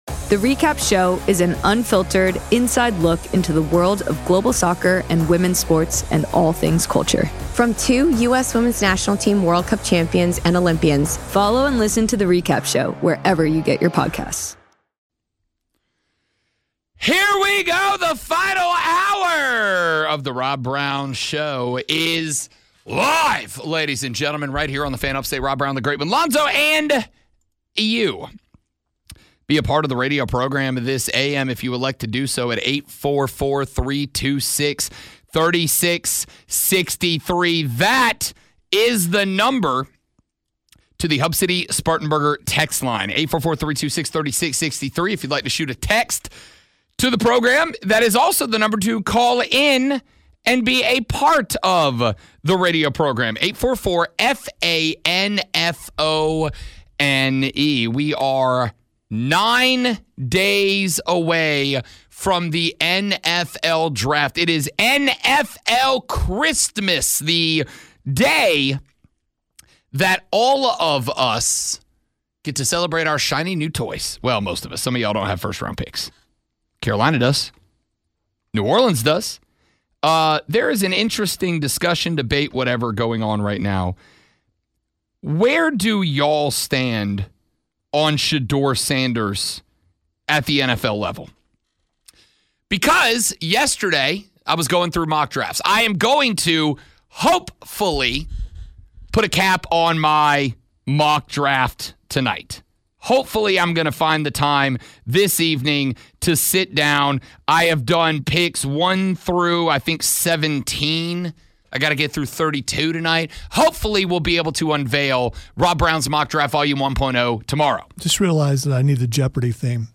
It's not yelling about stats or forcing contrived arguments for the sake of confrontation.&nbsp